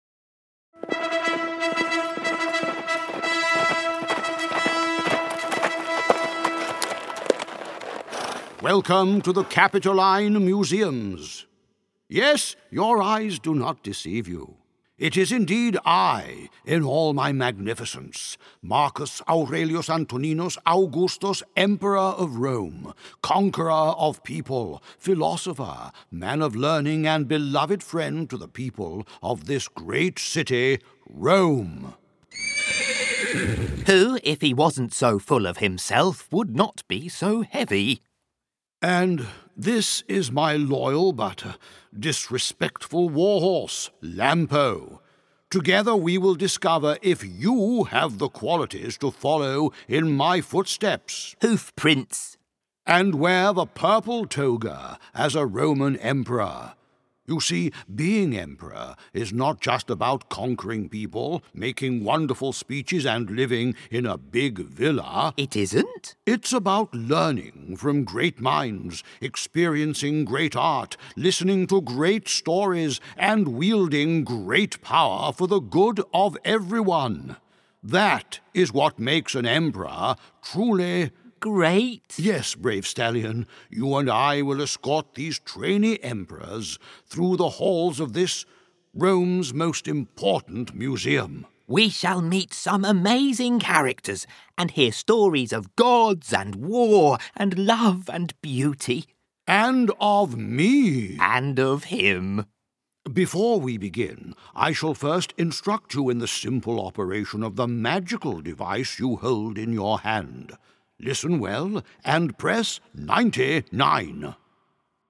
Special audio guides for children and their families at the Musei Capitolini.
The great emperor Marcus Aurelius, with his cute and witty steed called Lampo, will welcome children in the Musei.